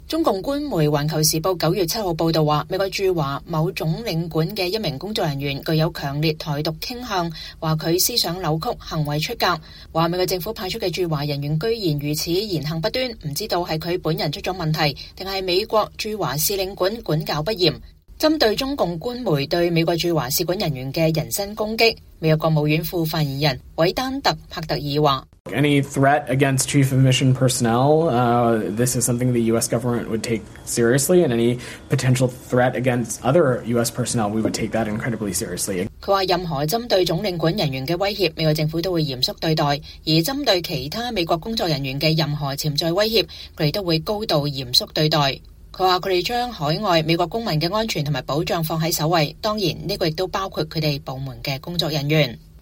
美國國務院副發言人韋丹特‧帕特爾
美國國務院副發言人韋丹特‧帕特爾說：“任何針對總領館人員的威脅，美國政府都會嚴肅對待，而針對其他美國工作人員的任何潛在威脅，我們都會高度嚴肅地對待。再說一次，我們將海外美國公民的安全和保障放在首位，當然，這也包括我們部門的工作人員。”